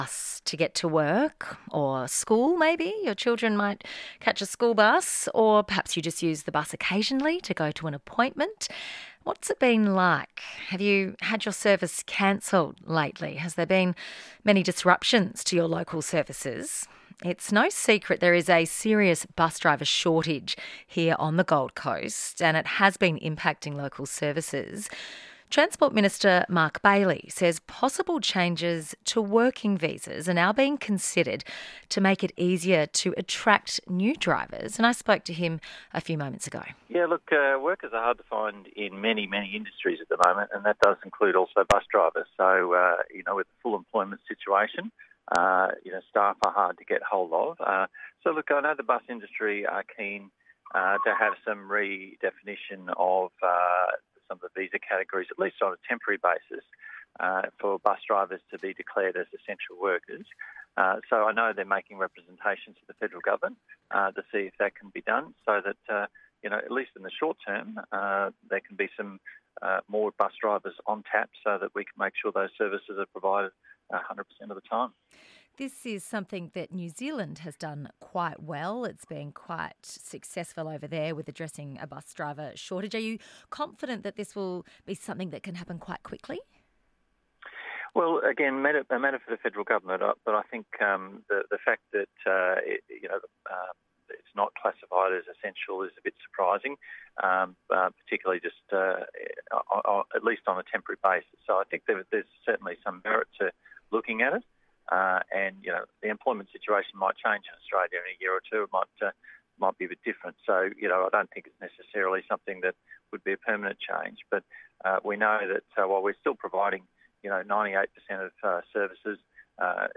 Interviews on Mornings ABC Gold Coast Radio 20th April 2023.